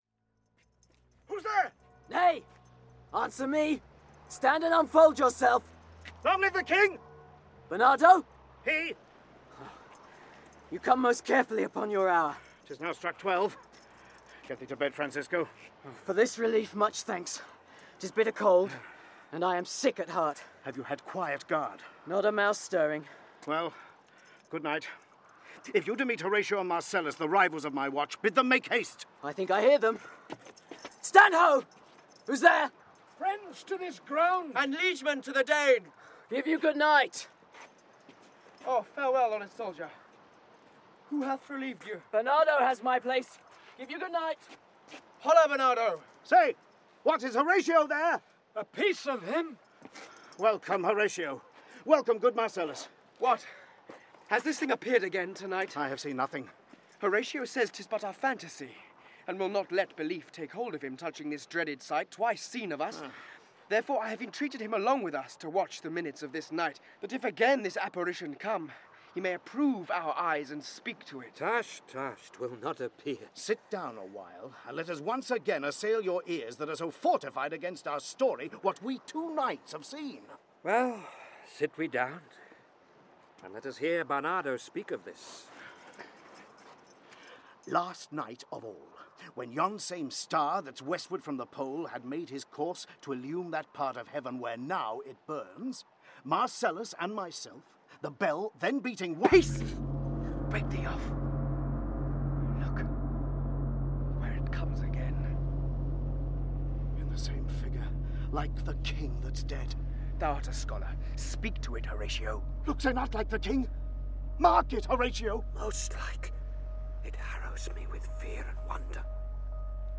Hamlet (EN) audiokniha
Ukázka z knihy